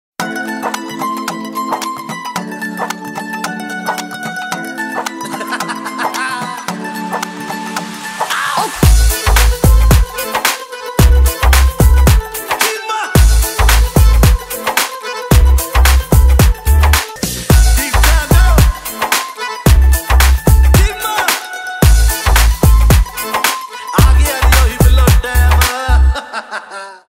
поп
индийские